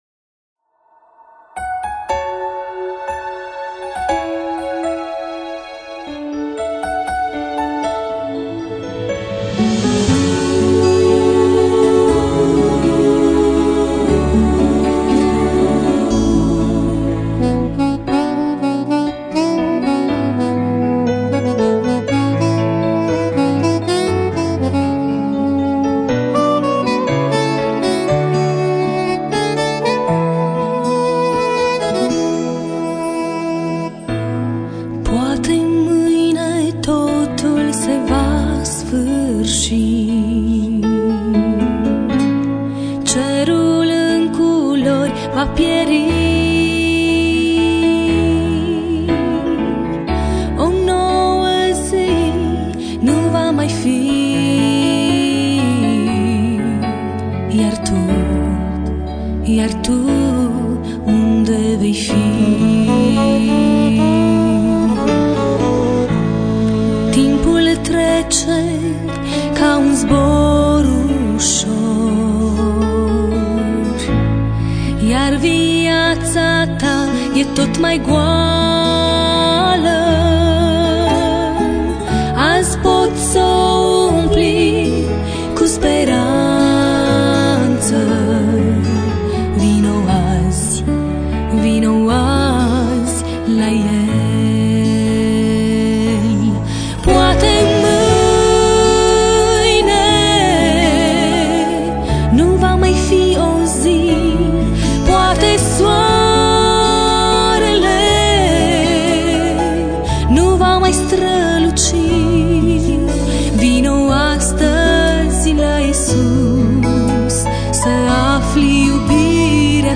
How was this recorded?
Next audio materials were recorded during our church services.